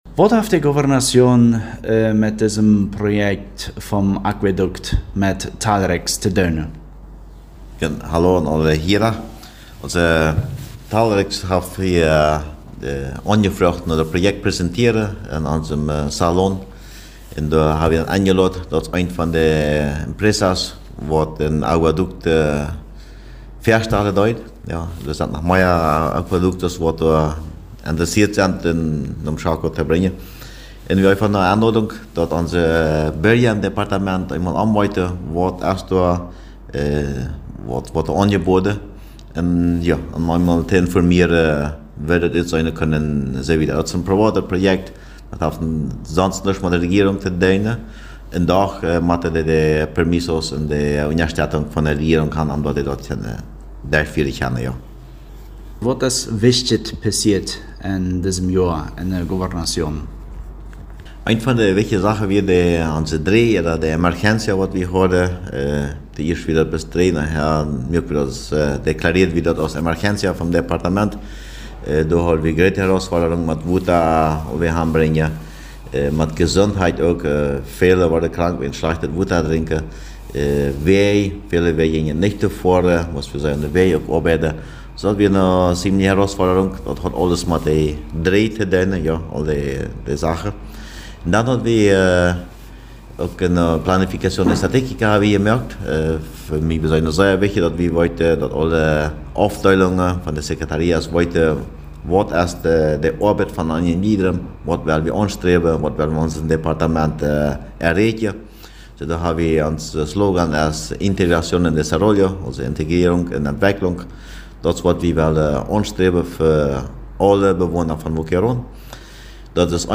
2023-12-20_Interview und Weihnachtsgruss Gobernador Boquerón